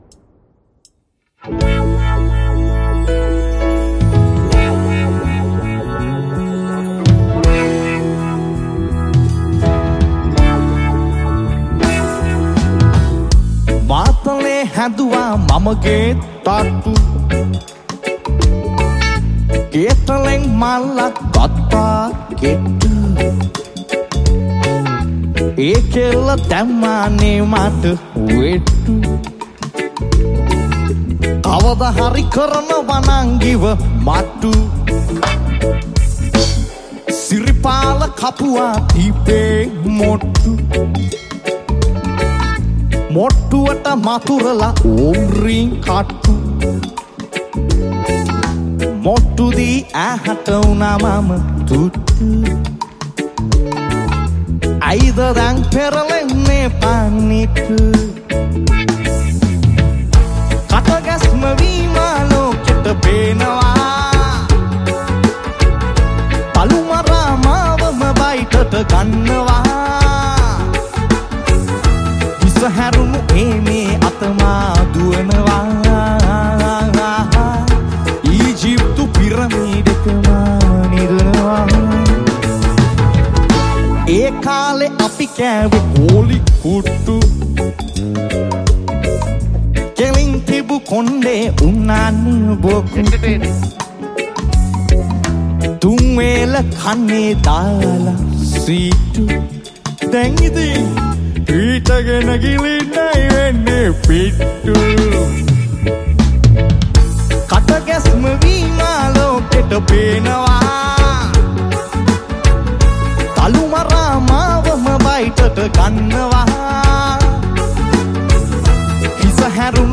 Blind Auditions